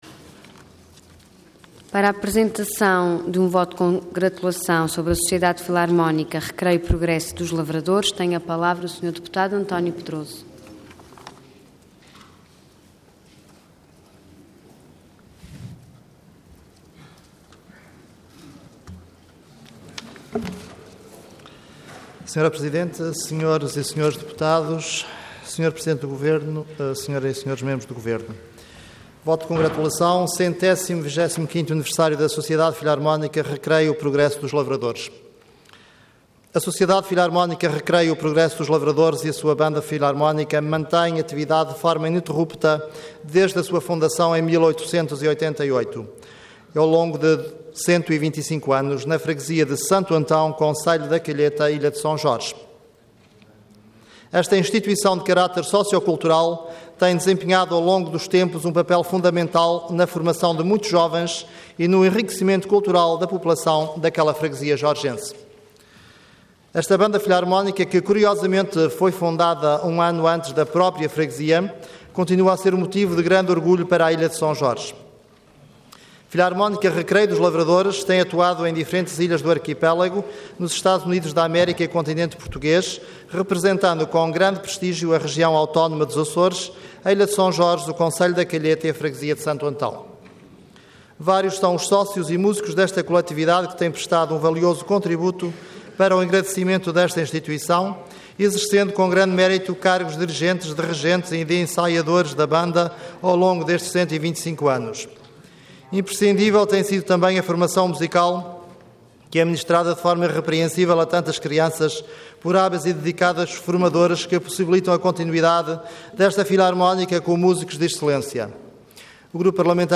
Intervenção Voto de Congratulação Orador António Pedroso Cargo Deputado Entidade PSD